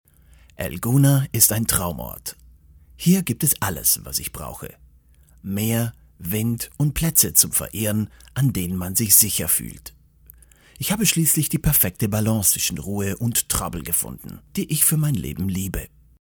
Swiss German voice over